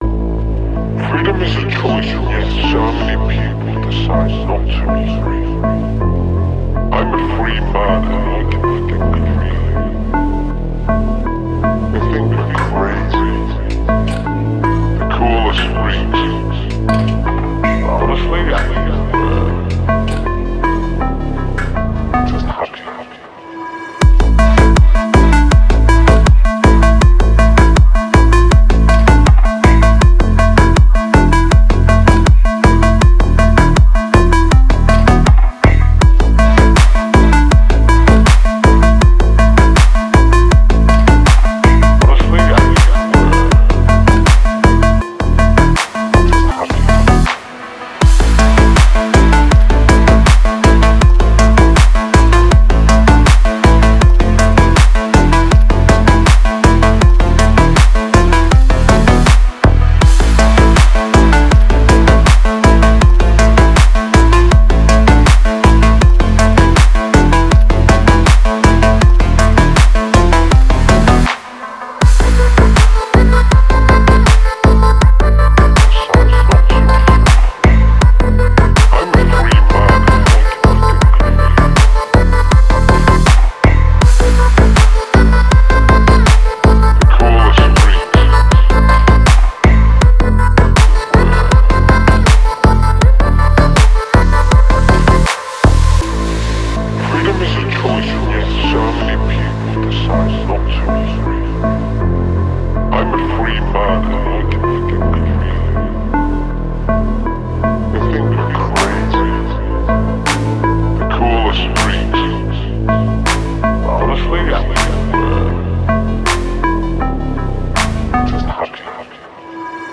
Клубная музыка